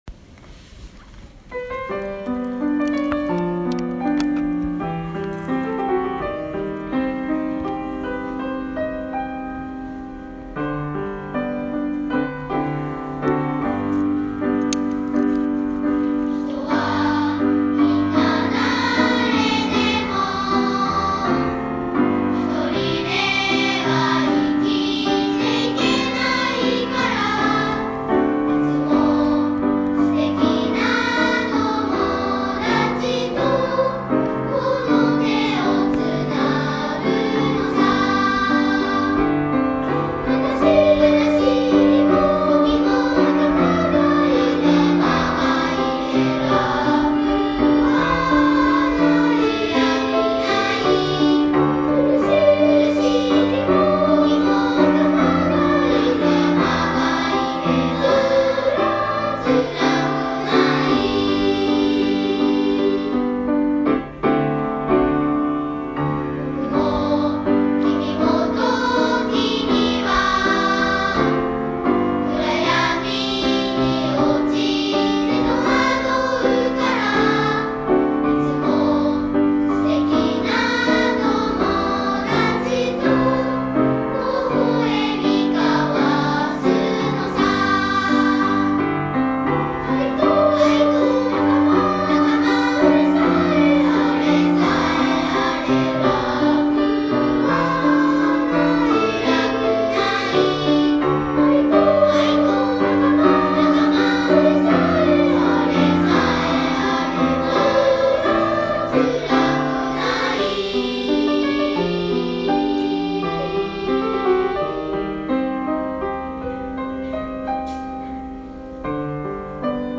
１１月１９日（土）の歌声発表会第２弾！４年生と２年生の発表をお届けします。